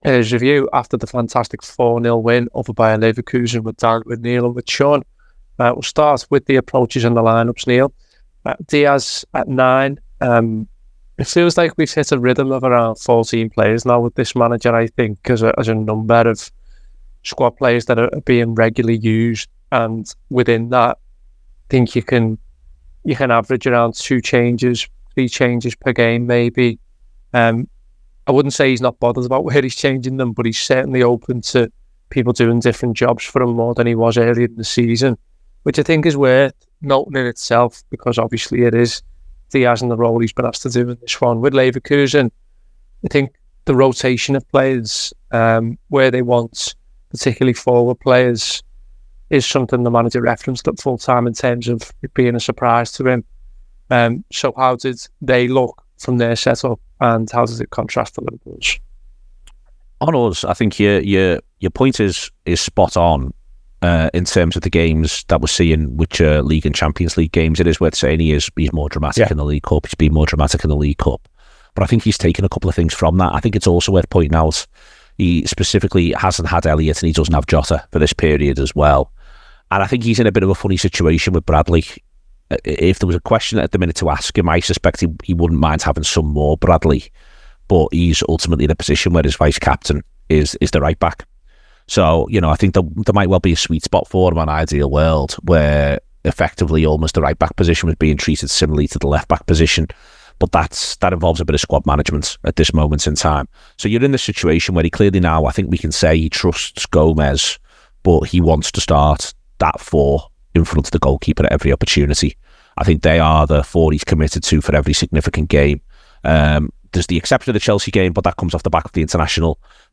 Below is a clip from the show- subscribe for more review chat around Liverpool 4 Bayer Leverkusen 0…